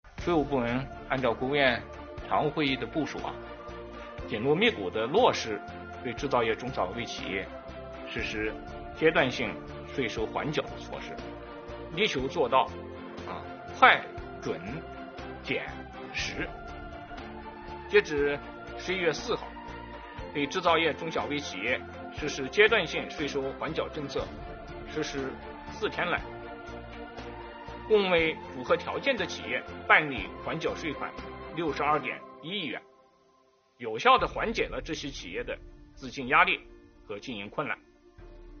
11月5日，国务院新闻办公室举行国务院政策例行吹风会，国家税务总局副局长王道树介绍制造业中小微企业缓税政策等有关情况，并答记者问。